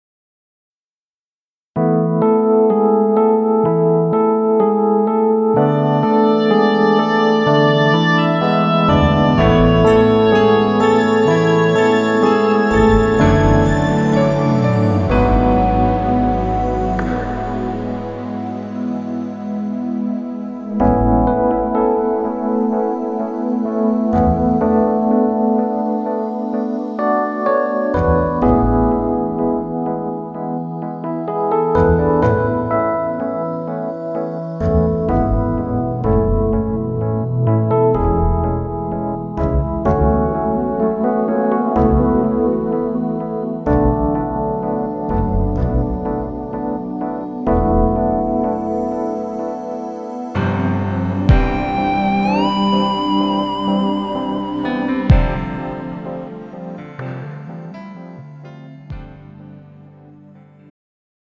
음정 -3키
장르 축가 구분 Pro MR